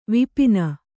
♪ vipina